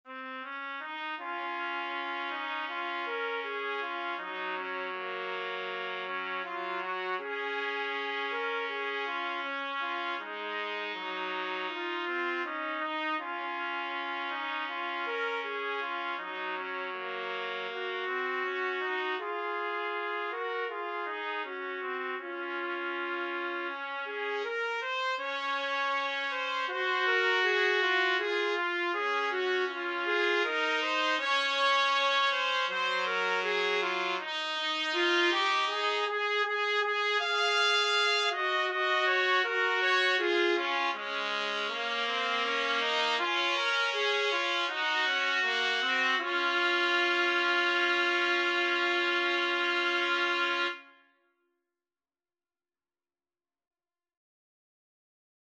Trumpet 1Trumpet 2
Db major (Sounding Pitch) Eb major (Trumpet in Bb) (View more Db major Music for Trumpet Duet )
4/4 (View more 4/4 Music)
Espressivo Andante
Traditional (View more Traditional Trumpet Duet Music)